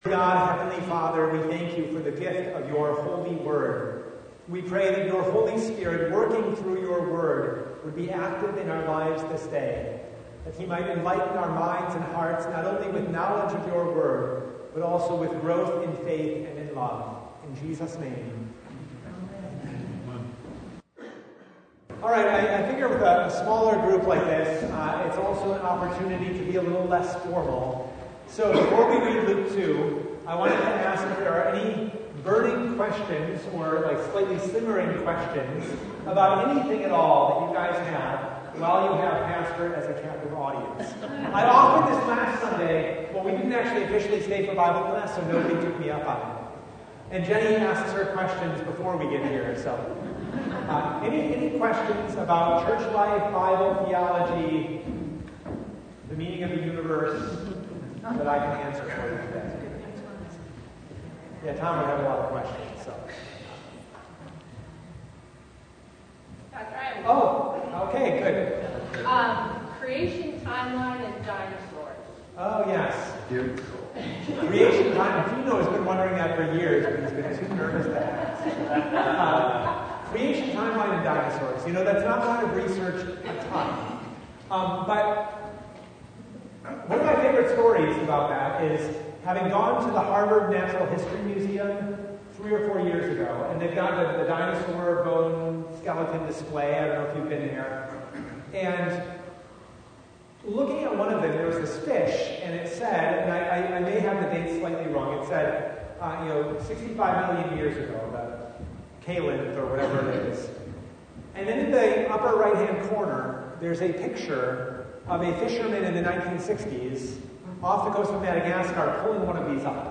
Passage: Luke 2:1-20 Service Type: Bible Study